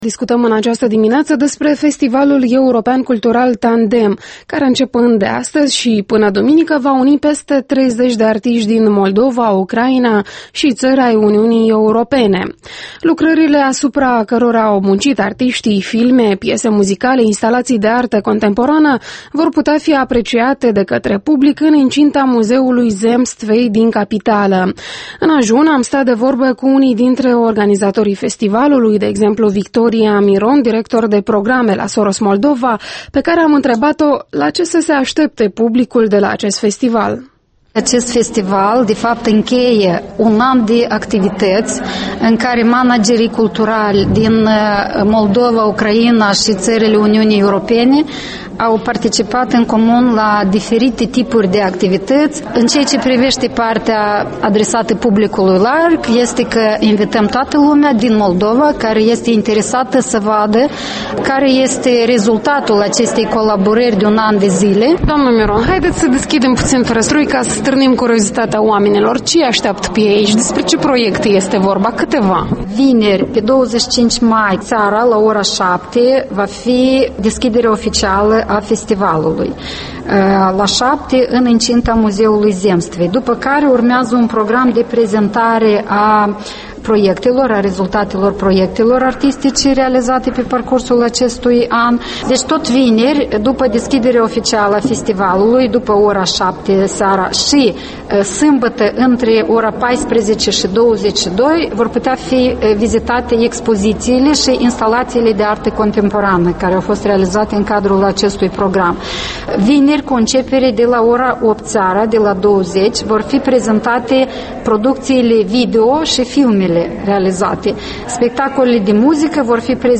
Interviul matinal la EL